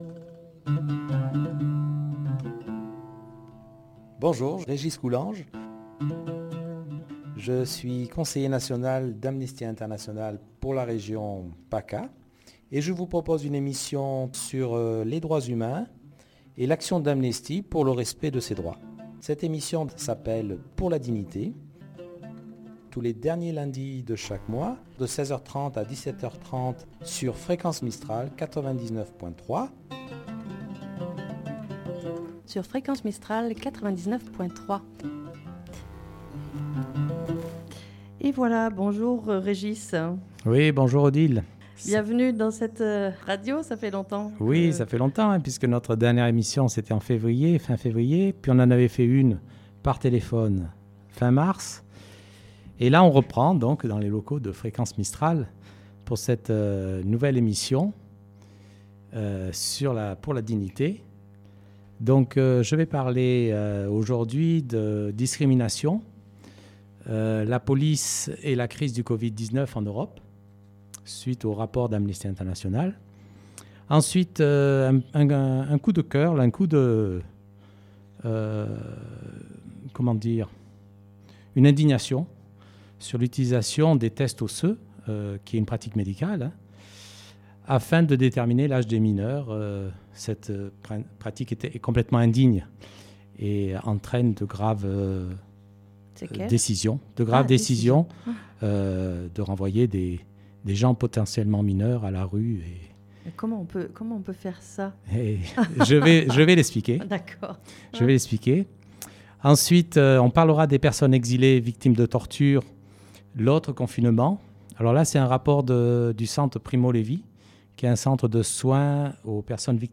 Discrimination : la police et la crise du covid 19 en Europe Pause musicale